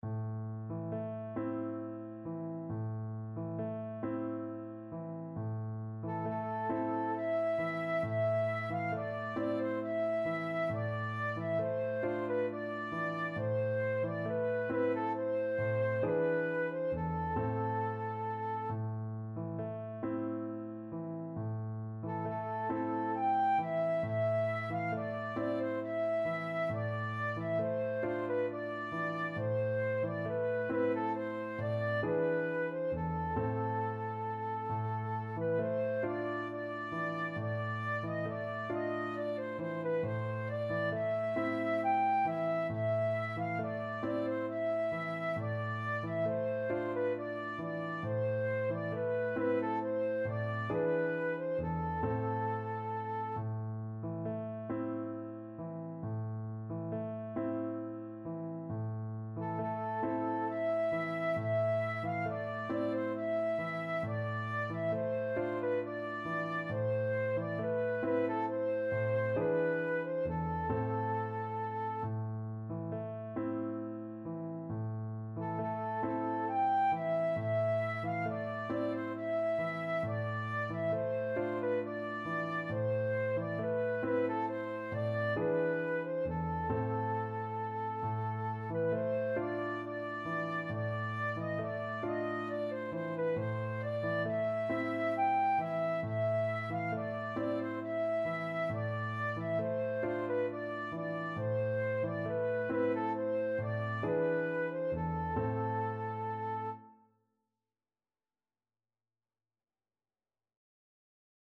Classical Trad. Sari Gelin Flute version
Traditional Music of unknown author.
A minor (Sounding Pitch) (View more A minor Music for Flute )
6/8 (View more 6/8 Music)
Gently rocking .=c.45
Classical (View more Classical Flute Music)
Turkish